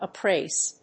アクセント・音節ùp・ráise